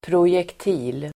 Uttal: [prosjekt'i:l (el. -jekt-)]